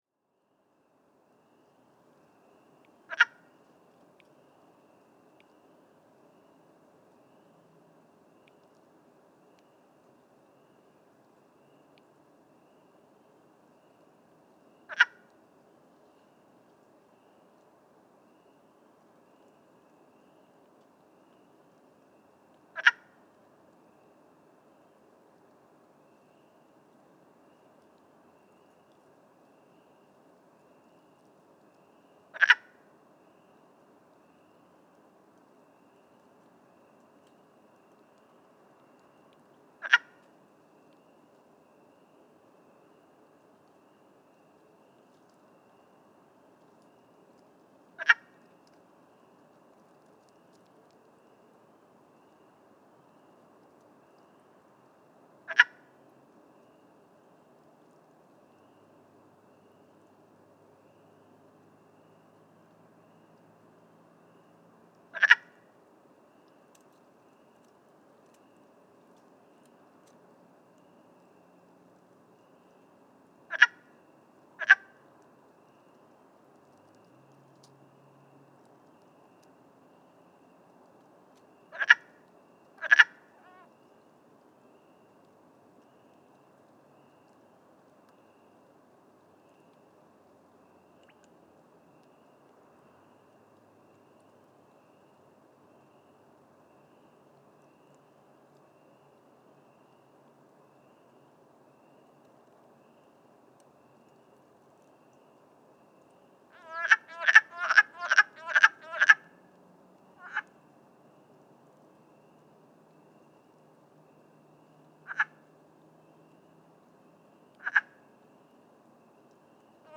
Grenouille des bois – Lithobates sylvaticus
Chant Rocher Blanc, Rimouski, QC. 6 mai 2019. 21h00.